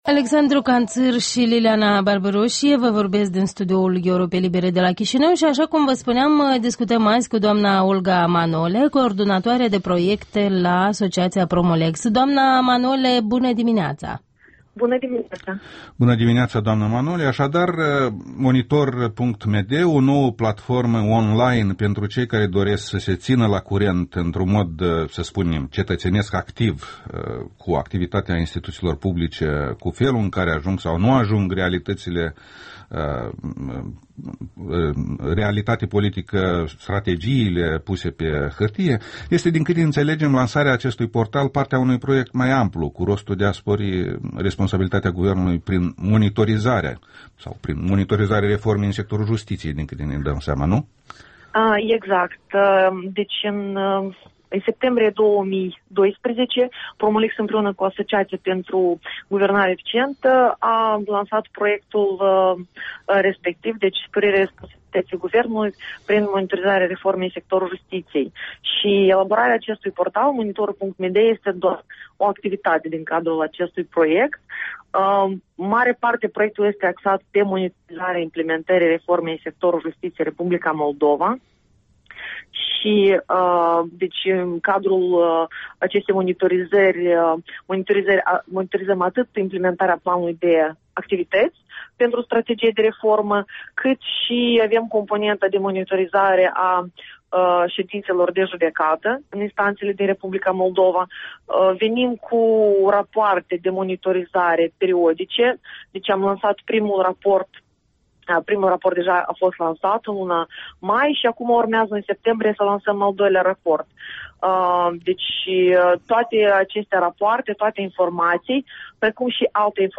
Interviul matinal la Europa Liberă